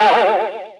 SM64UnusedBoing.wav.ogg